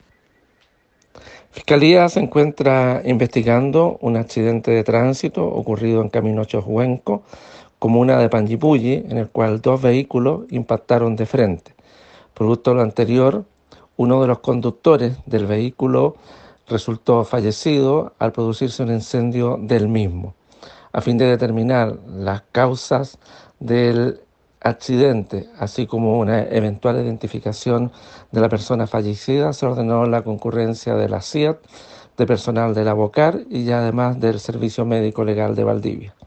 Audio Fiscal.